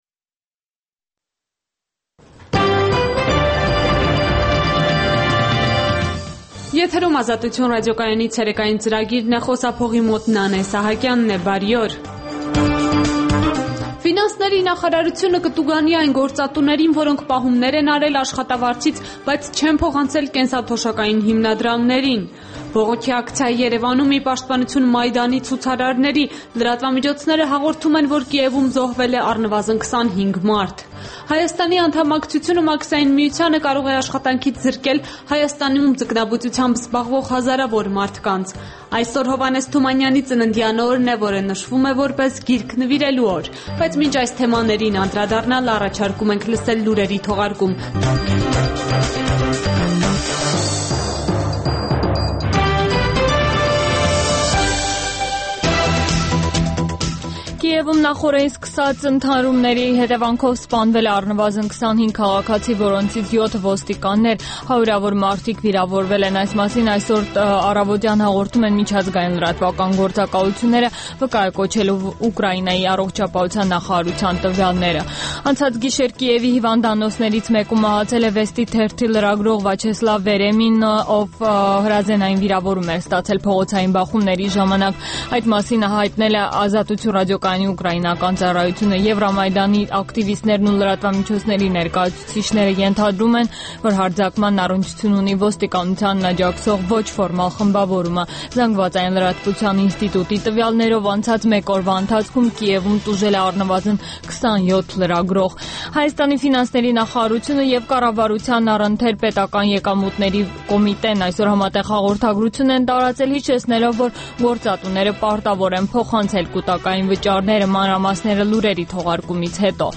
Տեղական եւ միջազգային լուրեր, երիտասարդությանը առնչվող եւ երիտասարդությանը հուզող թեմաներով ռեպորտաժներ, հարցազրույցներ, երիտասարդական պատմություններ, գիտություն, կրթություն, մշակույթ: